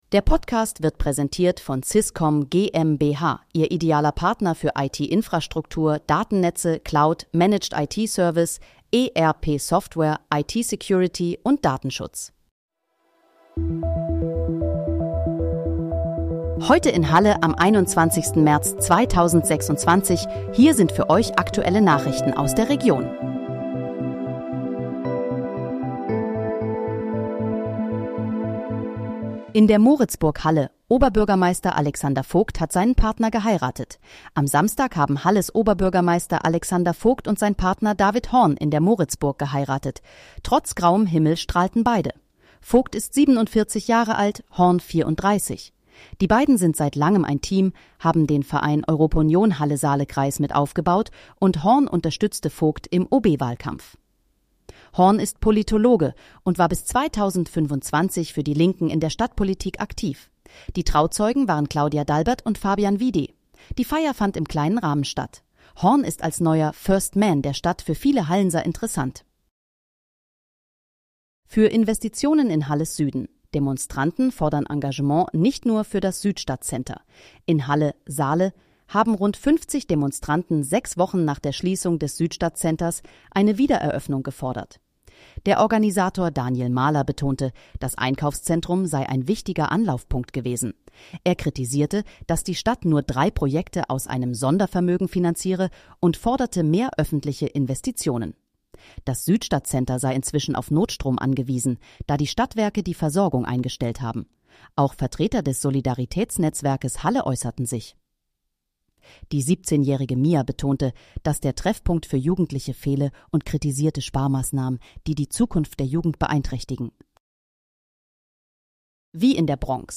Nachrichten